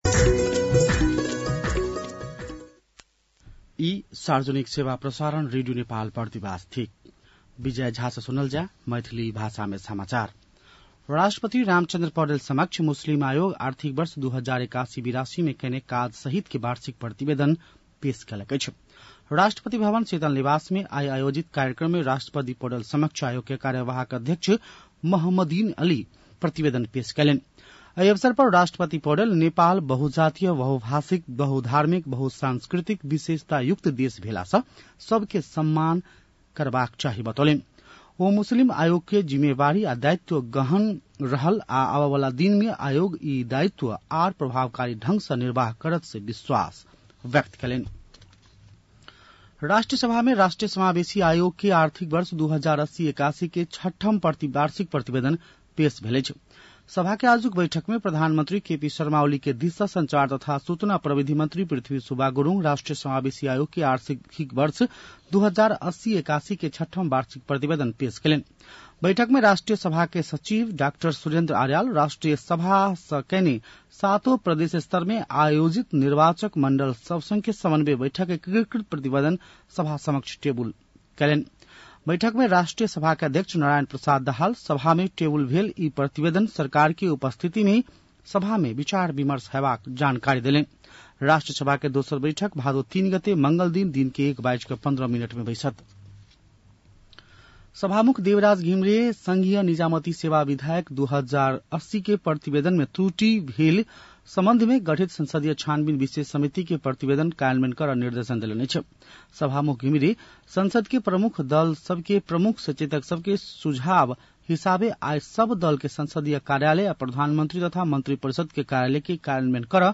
मैथिली भाषामा समाचार : ३० साउन , २०८२
Maithali-NEWS-04-30.mp3